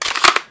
assets/psp/nzportable/nzp/sounds/weapons/thomp/magin.wav at af6a1cec16f054ad217f880900abdacf93c7e011